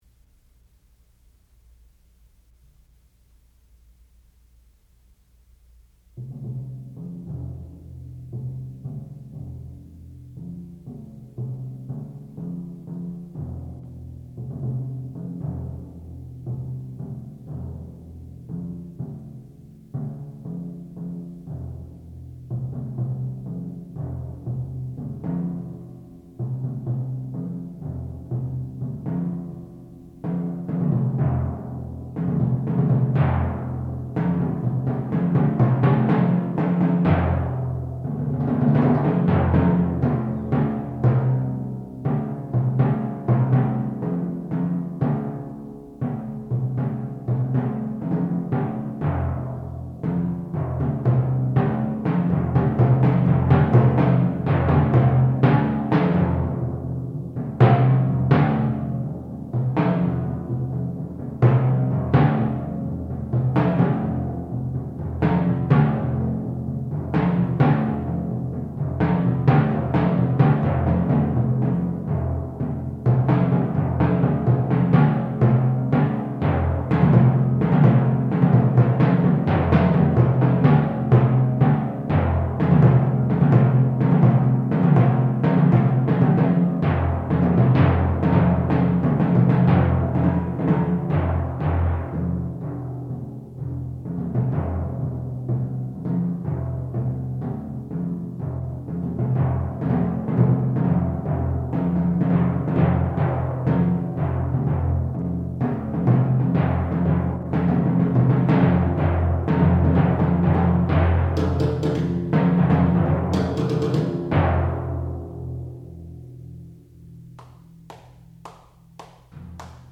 classical music
timpani
Master's Recital